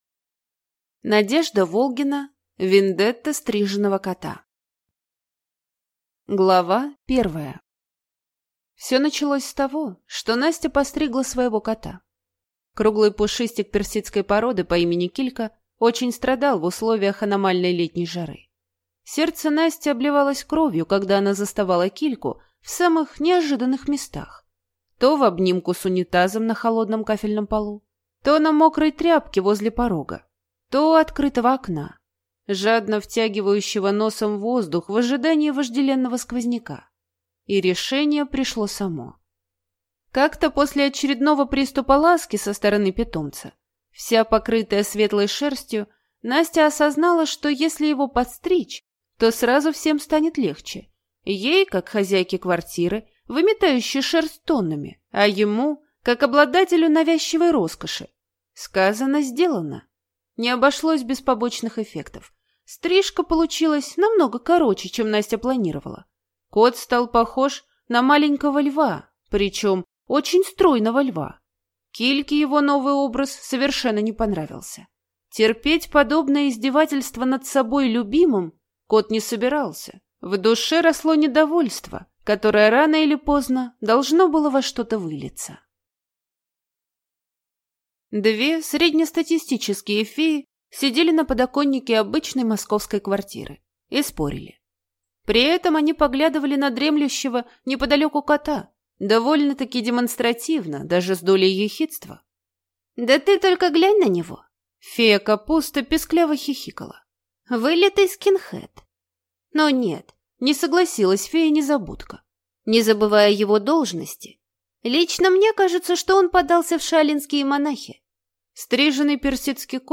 Аудиокнига Вендетта стриженого кота | Библиотека аудиокниг